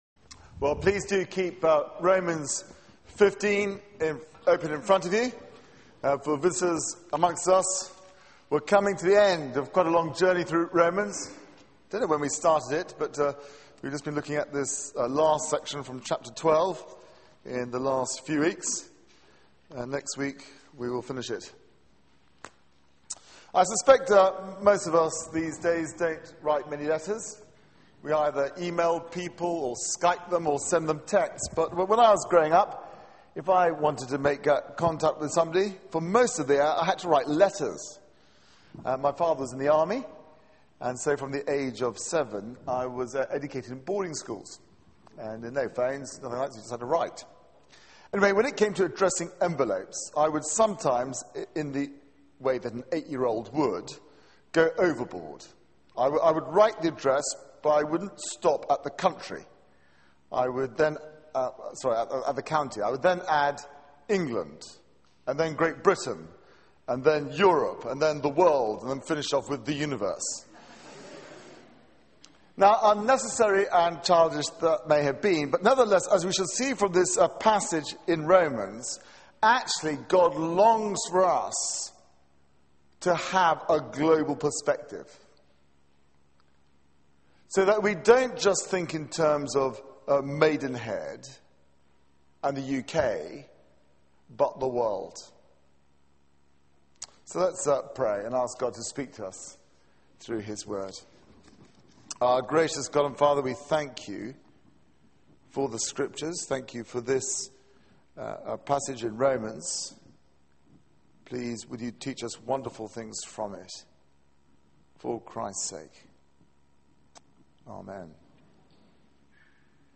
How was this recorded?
Media for 6:30pm Service on Sun 21st Oct 2012 18:30 Speaker